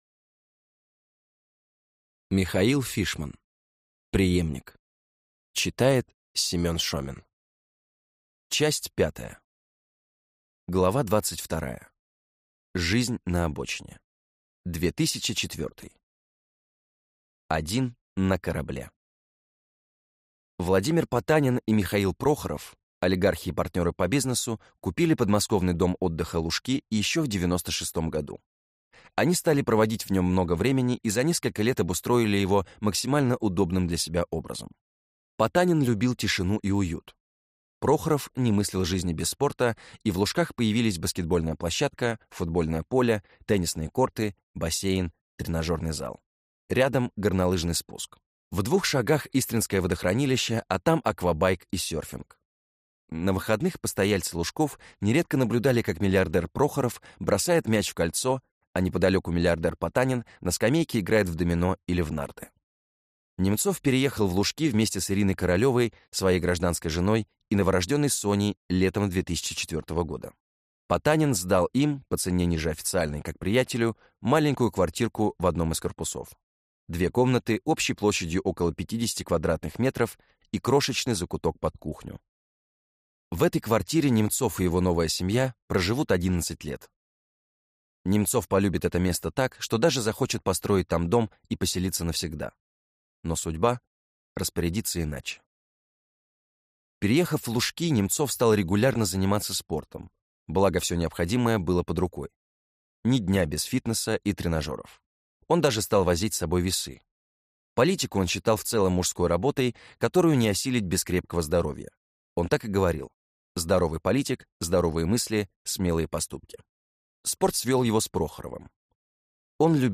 Аудиокнига Преемник (Часть 5) | Библиотека аудиокниг